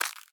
Sfx_creature_babypenguin_foot_ice_01.ogg